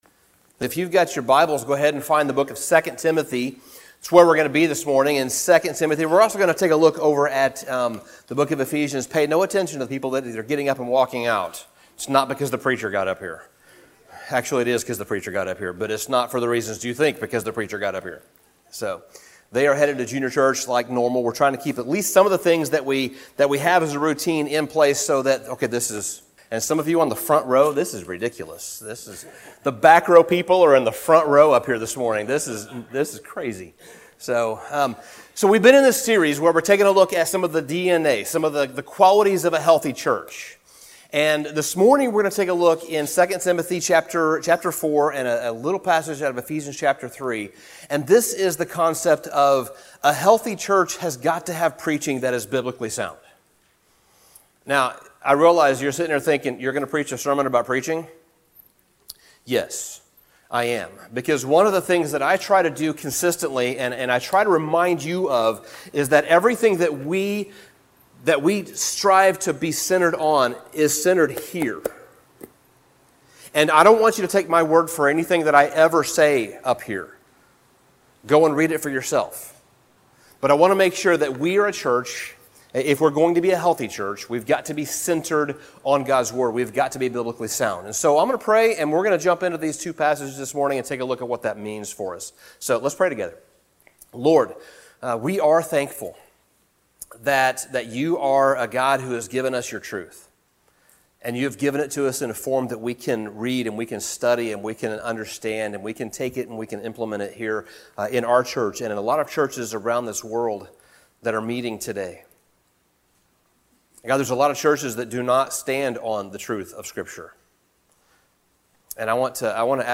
Sermon Summary One solid indicator of a healthy church is that it is grounded firmly in the Word of God, and that Word is communicated clearly and accurately through the teaching and preaching.